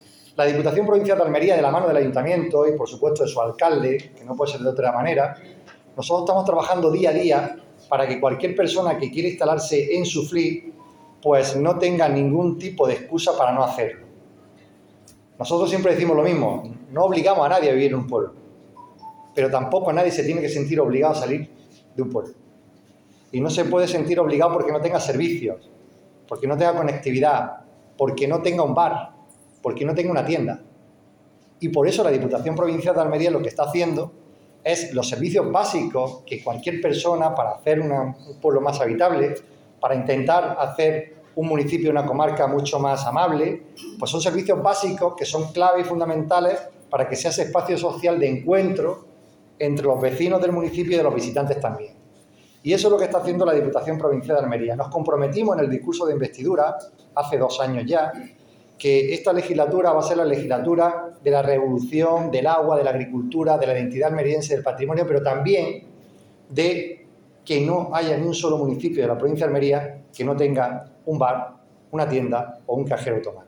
El presidente de la Diputación Provincial, Javier A. García y el alcalde de Suflí, Raúl Guirao, acompañados por diputados provinciales, miembros de la Corporación Municipal, alcaldes de municipios del Almanzora y numerosos vecinos, han inaugurado este nuevo servicio público ubicado en la Plaza de la Constitución de Suflí, en los bajos del Ayuntamiento que, además del espacio dedicado a la venta de productos, dispone de obrador para la elaboración de pan o pizzas artesanales.